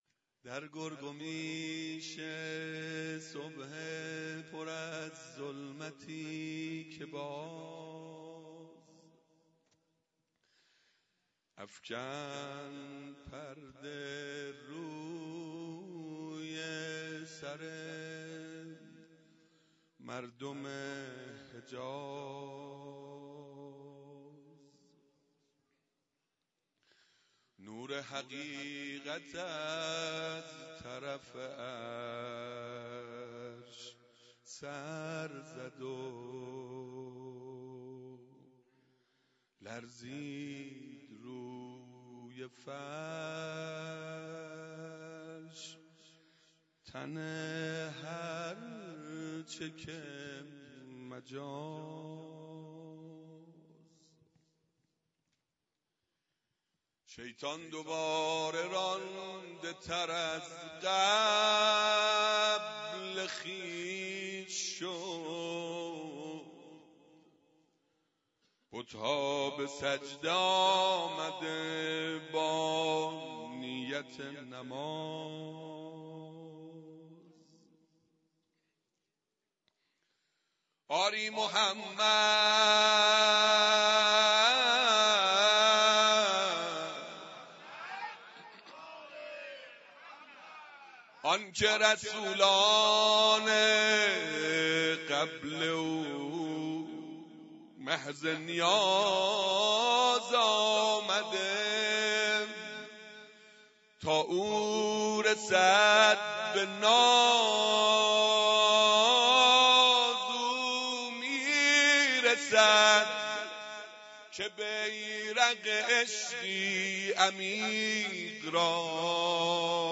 ولادت رسول الله و امام صادق (صلی الله علیهما وآلهما)_مدح خوانی